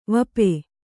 ♪ vape